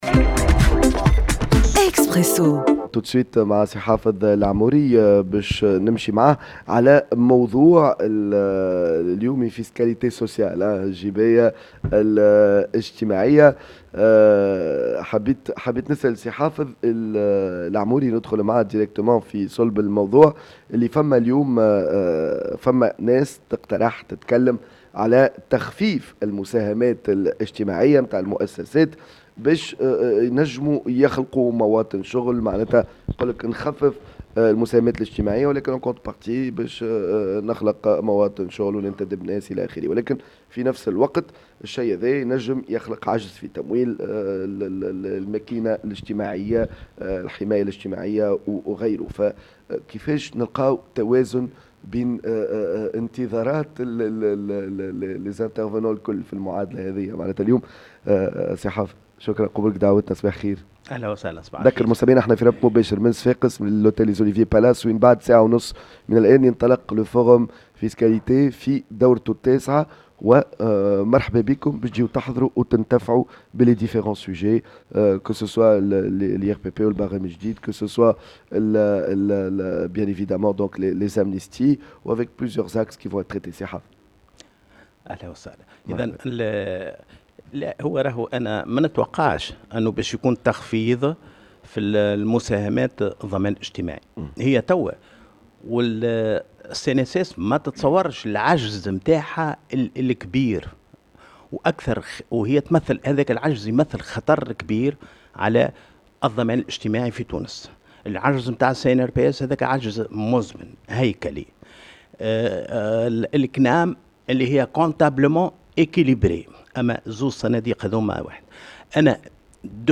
أكثر تفاصيل مع حافظ العموري، وزير التشغيل والتكوين المهني السابق في برنامج اكسبرسو مباشرة من منتدى الجباية في نسخته التاسعة